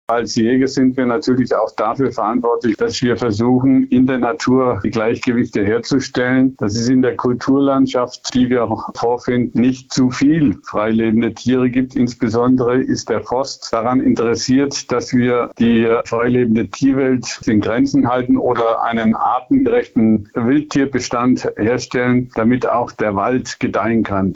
Interview: Aufgaben eines Jägers - PRIMATON